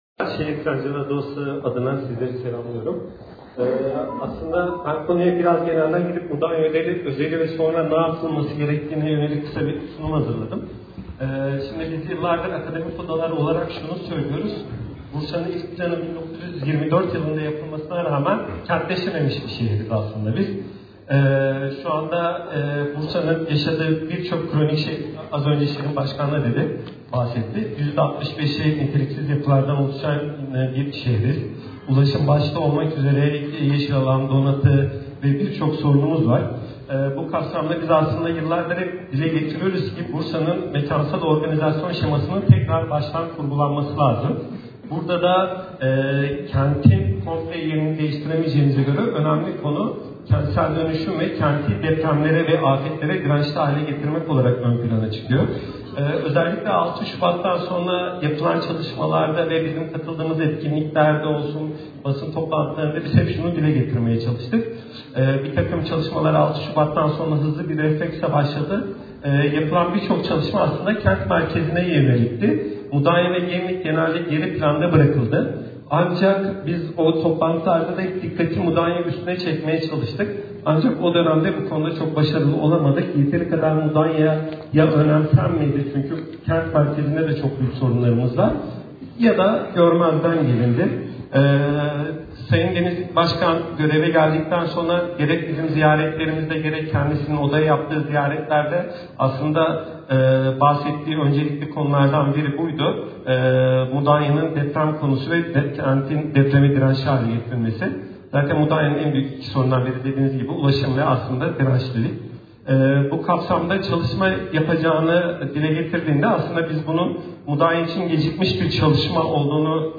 Deprem Analizi Toplantısı Konuşması